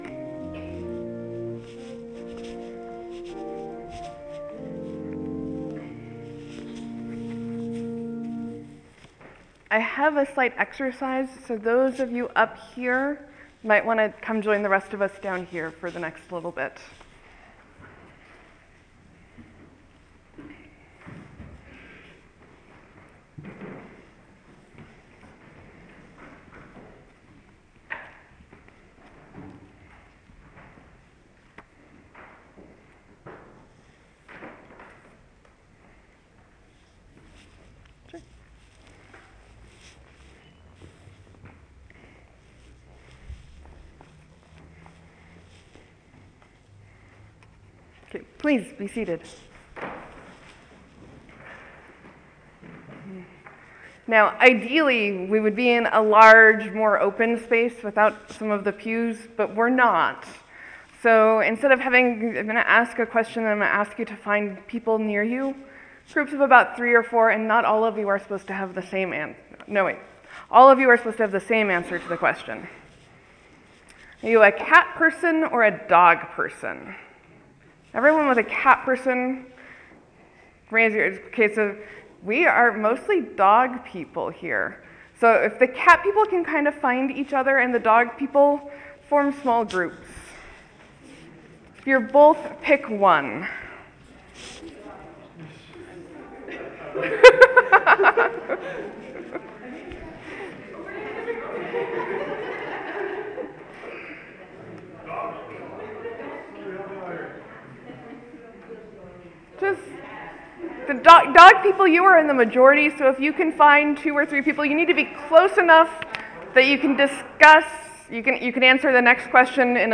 Parts of this may be confusing or mostly background noise.
Sermon: I preached on the Gerasene demoniac, and started with wondering how he looked.